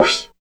85 MOD CYM-L.wav